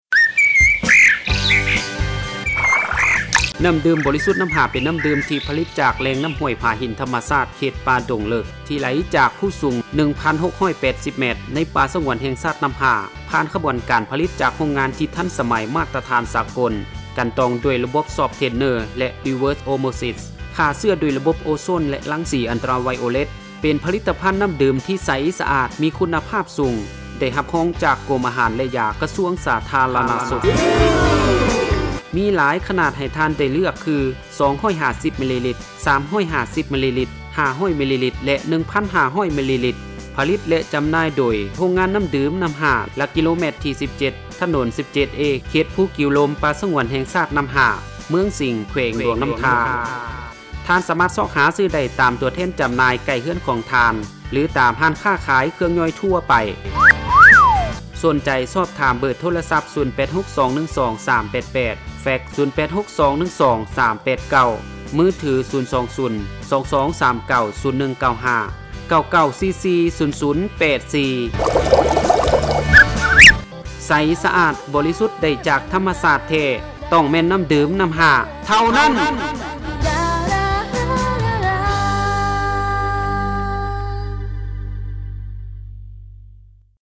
ລາຍການໂຄສະນານໍ້າດື່ມນໍ້າຫ້າທາງວິທະຍຸ